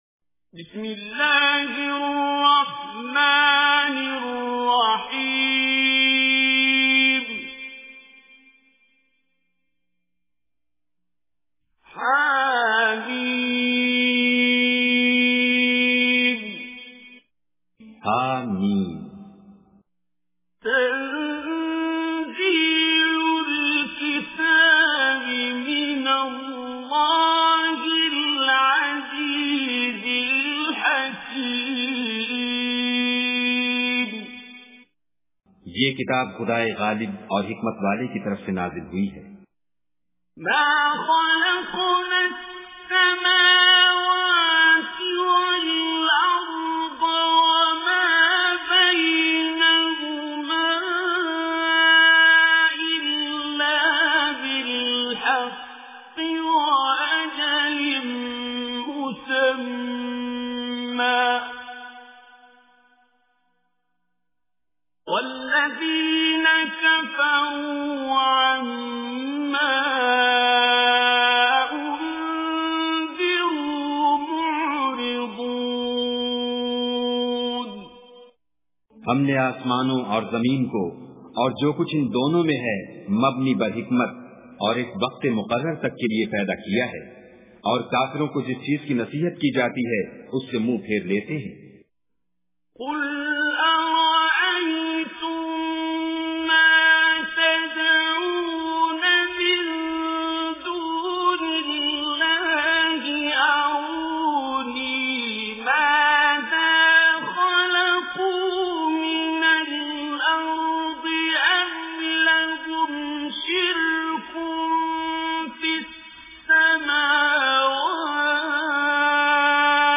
Surah Al Ahqaf Recitation with Urdu Translation
Surah Al Ahqaf is 46th chapter of Holy Quran. Listen online and download mp3 tilawat / recitation of Surah Al Ahqaf in the voice of Qari Abdul Basit As Samad.